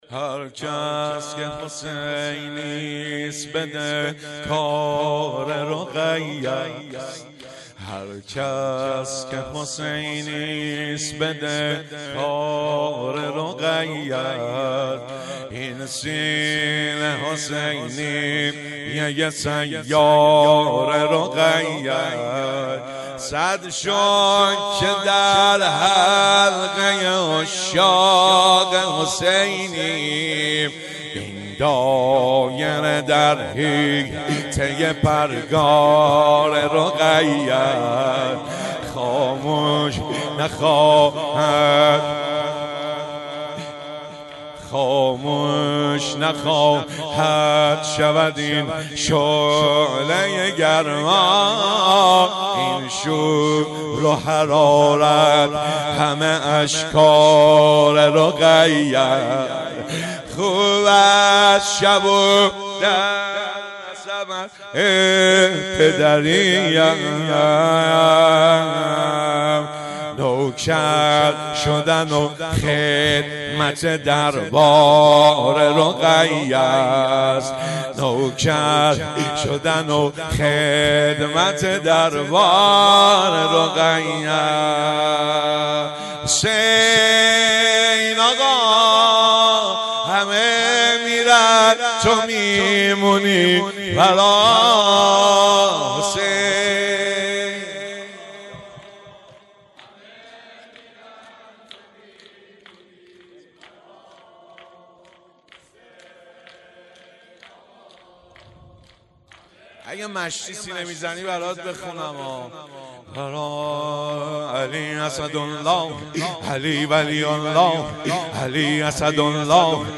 شور
مراسم شهادت امام جعفر صادق علیه السلام ۱۴۰۳